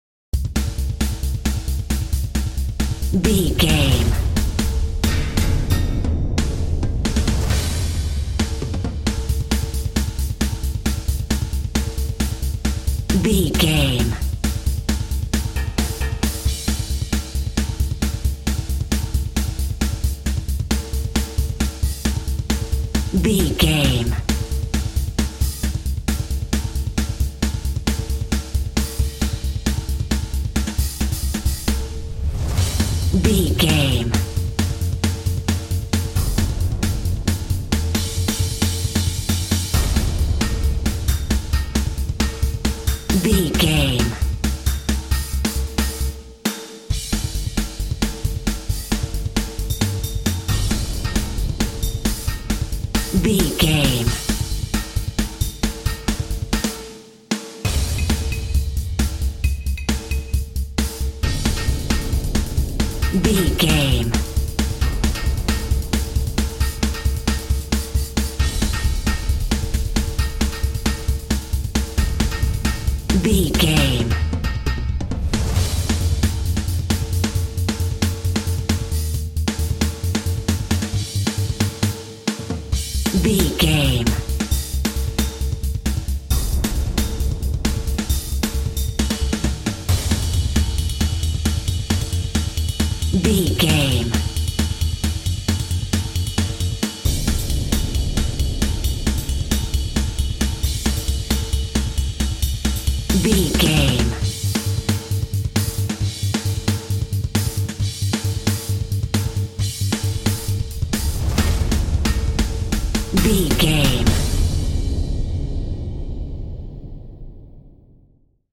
Aeolian/Minor
Fast
intense
8bit
aggressive
energetic
synth
drums